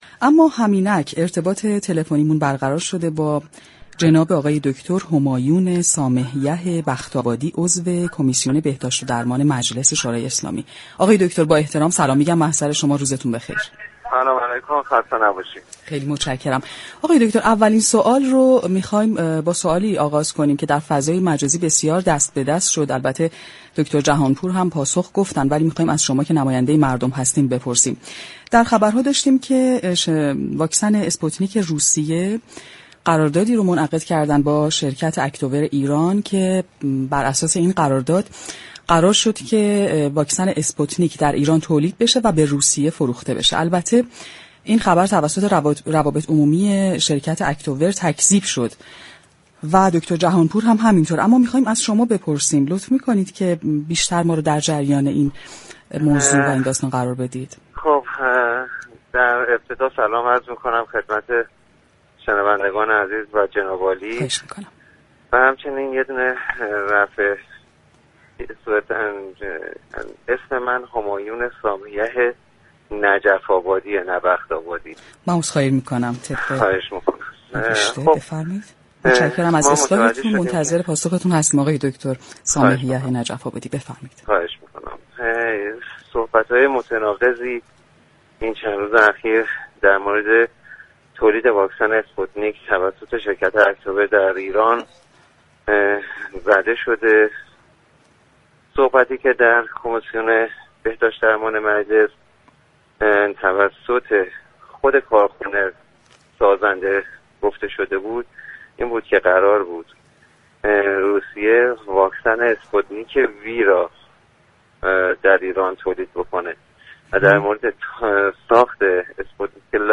به گزارش پایگاه اطلاع رسانی رادیو تهران؛ همایون سامه یح نجف آبادی، نماینده مجلس یازدهم در گفتگو با برنامه تهران ما سلامت رادیو تهران درباره حواشی تولید واكسن اسپوتنیك روسیه در ایران توسط شركت اكتور گفت: اظهارات متناقضی در چند روز اخیر توسط شركت اكتور ایران در راستای تولید واكسن اسپوتنیك ارائه شده است.